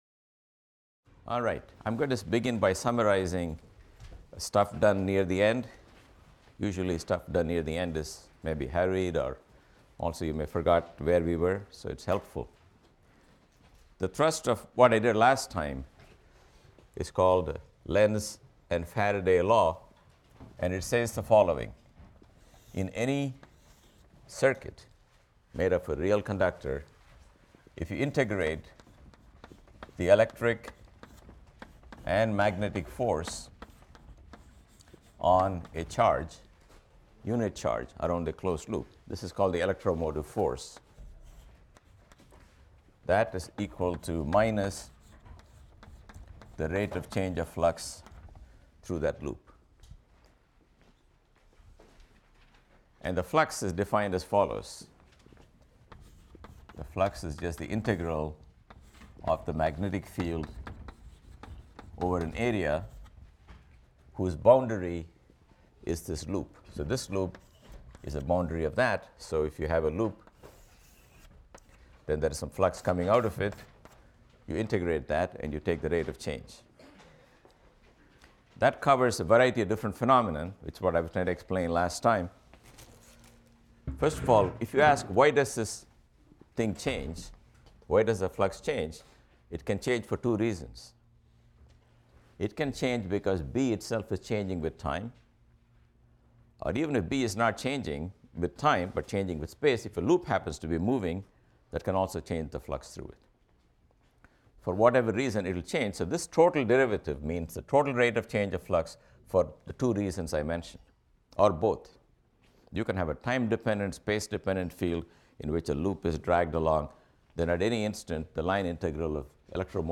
PHYS 201 - Lecture 11 - Lenz’s and Faraday’s Laws | Open Yale Courses